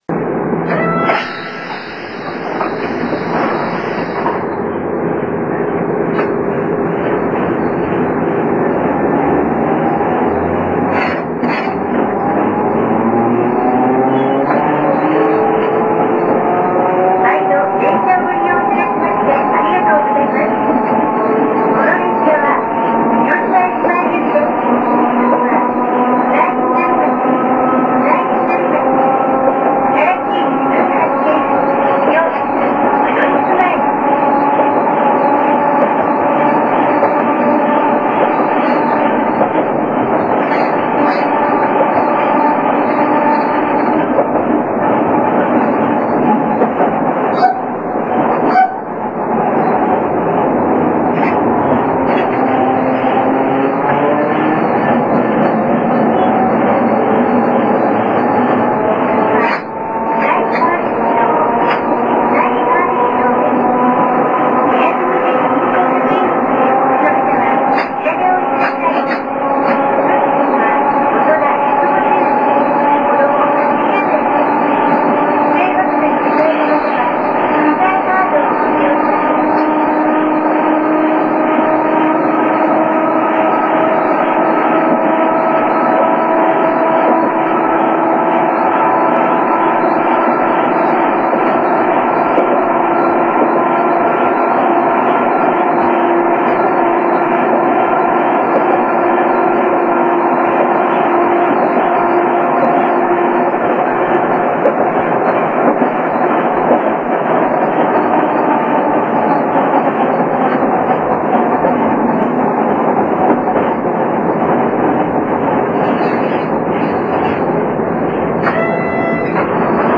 ■車内で聴ける音■
警笛音もしっかり録音できました。